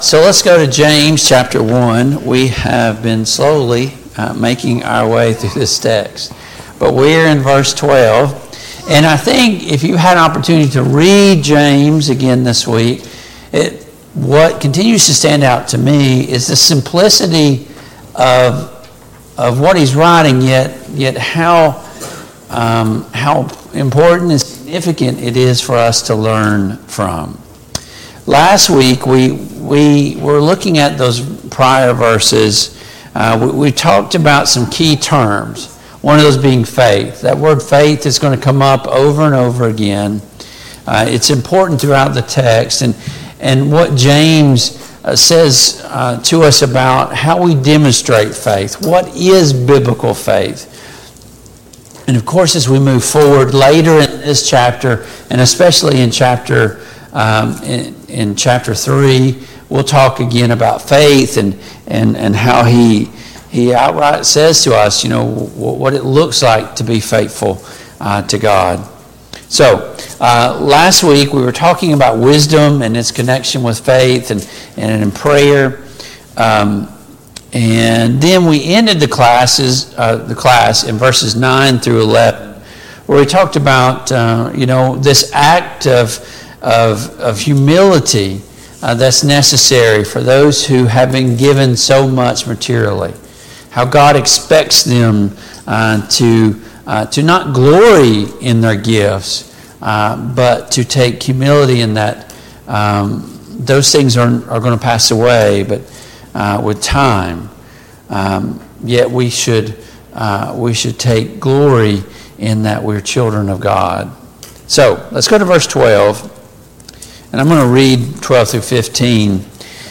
James 1:12-15 Service Type: Family Bible Hour Topics: Sin and Temptation , Trials « Am I becoming more like Jesus?